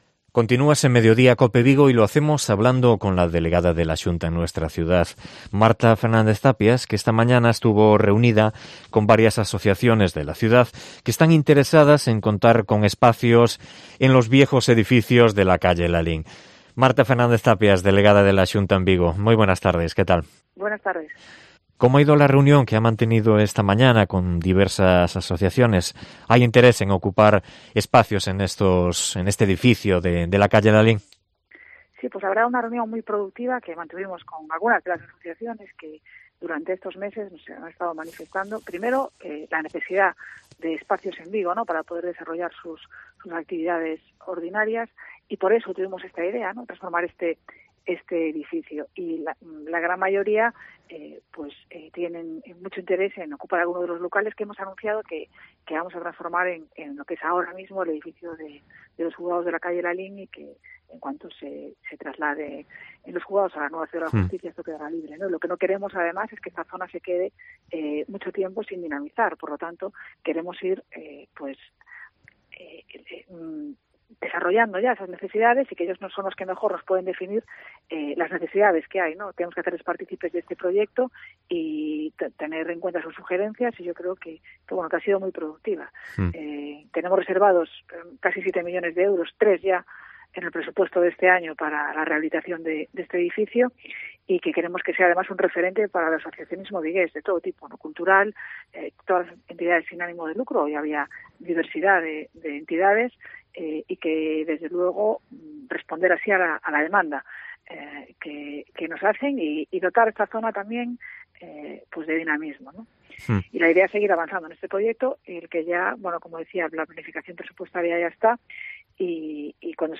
Entrevista a Marta Fernández-Tapias, delegada de la Xunta en VIgo